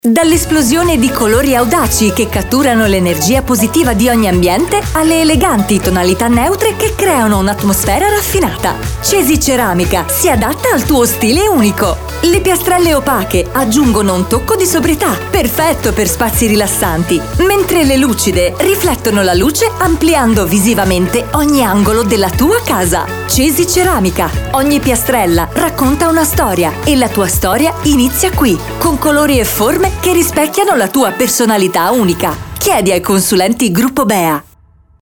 CesiSpot.mp3